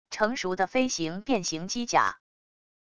成熟的飞行变形机甲wav音频